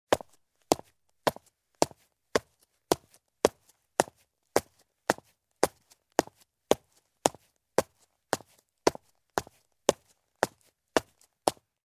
Звуки шагов по асфальту
Звук мужских шагов по асфальту в звонких лакированных ботинках